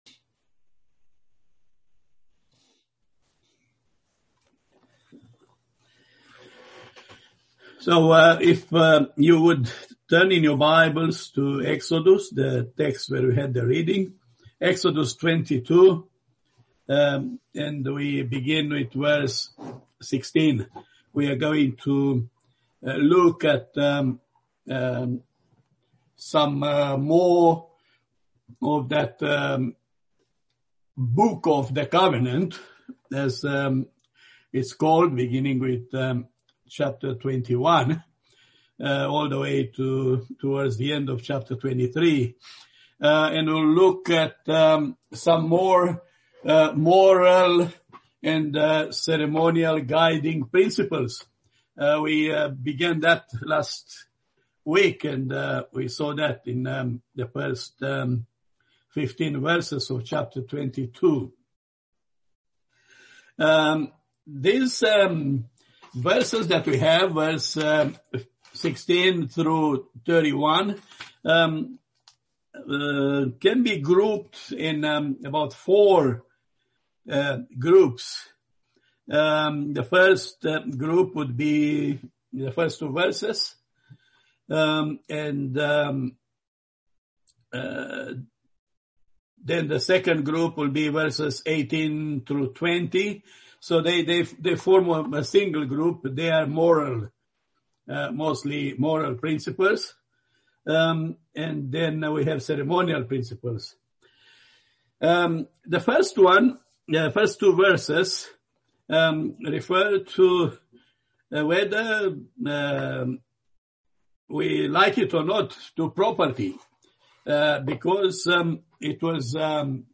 Passage: Exodus 22:16-31 Service Type: Sunday Evening